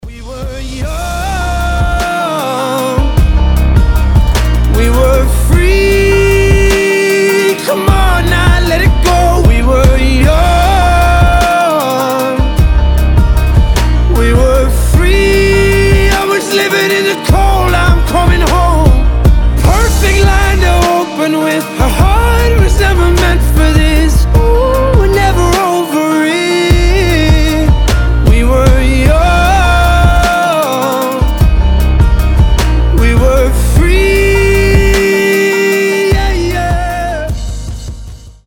• Качество: 320, Stereo
красивый мужской голос
мелодичные
фолк
alternative
ностальгия
соул